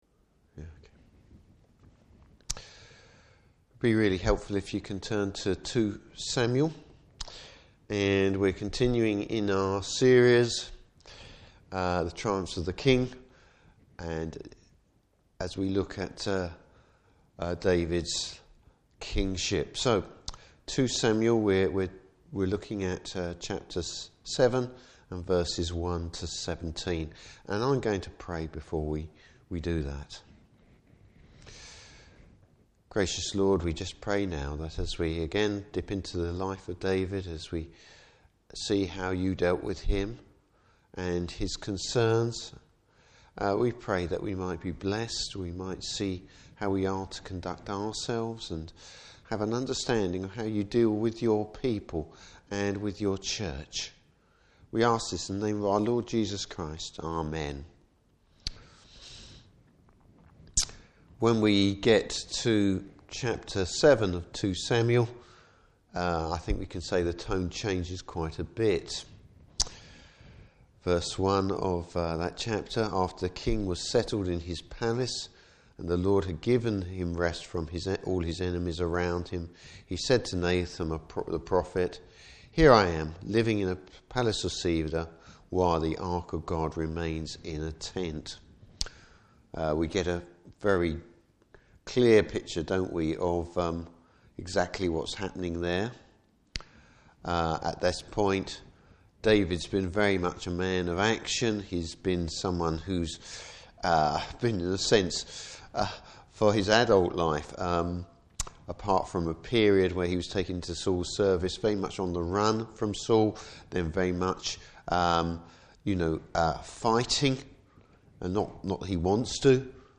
Service Type: Evening Service David’s good intentions, God’s timing.